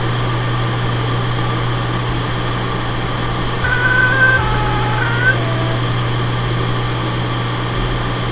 Nepravidelne vydava taky divny zvuk (
Jedná se o teplotní rekalibraci, zvuk je zcela v normálu.